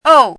chinese-voice - 汉字语音库
ou4.mp3